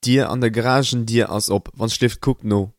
garagedoor_open.mp3